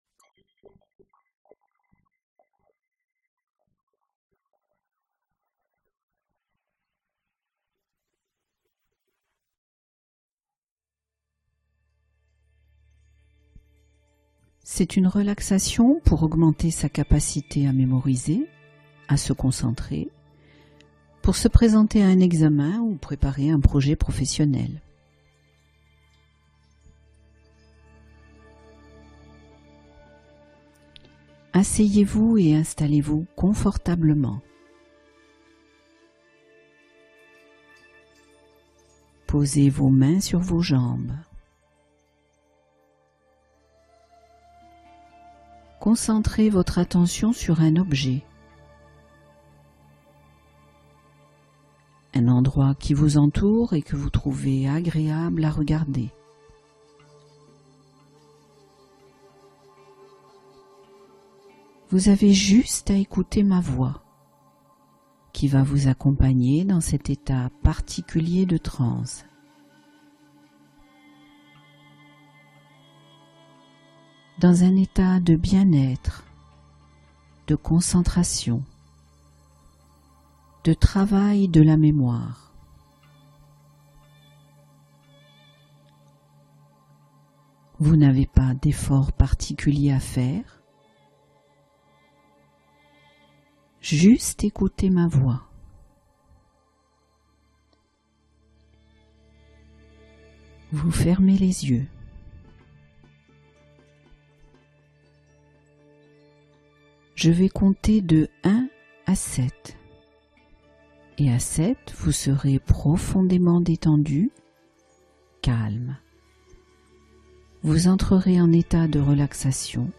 Mémoire boostée x3 : l'hypnose que les étudiants écoutent avant les examens